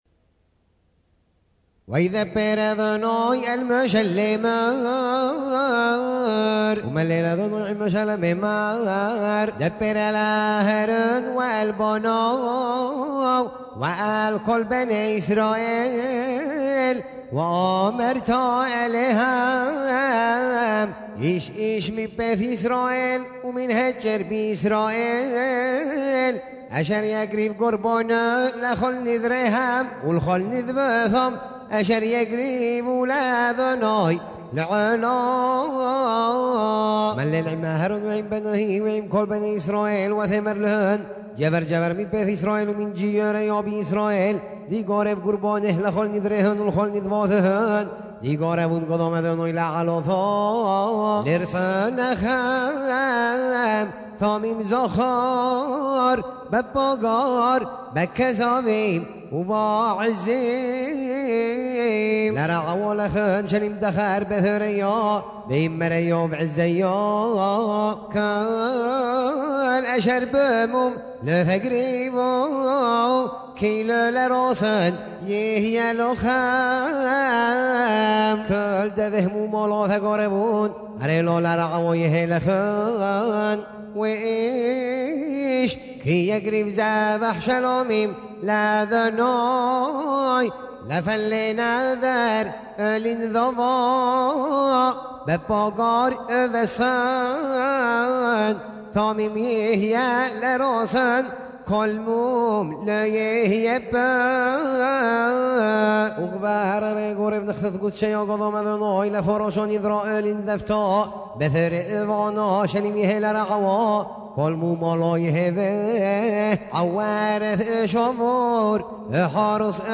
ז'אנר: Blues.